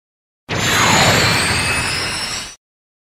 PLAY full cowling Meme Sound Effect for Soundboard